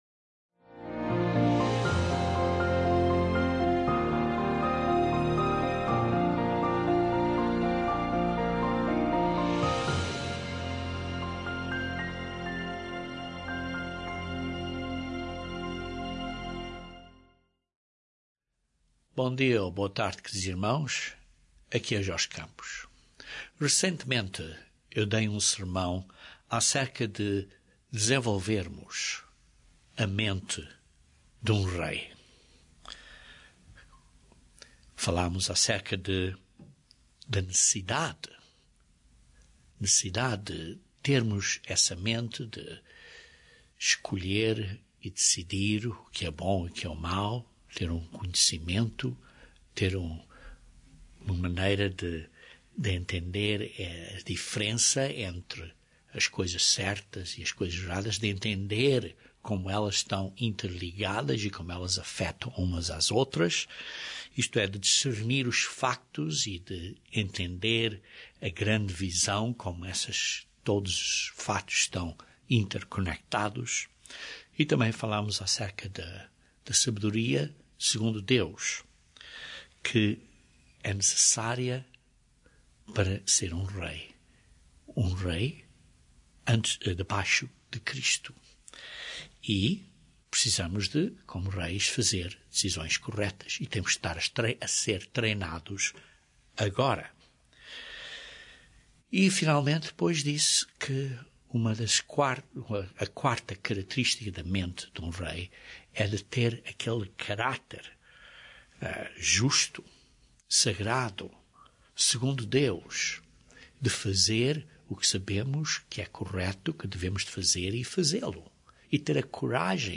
Este sermão descreve princípios importantes para desenvolvermos este caráter de Deus.